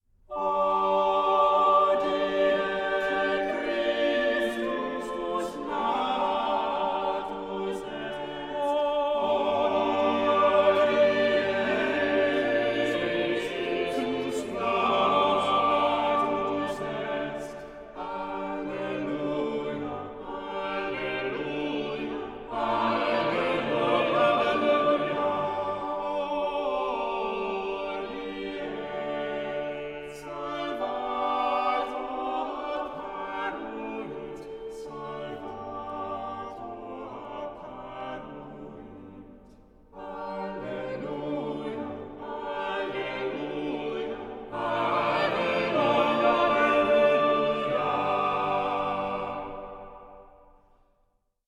Stereo/Multichannel
Renaissance Choral Music for Christmas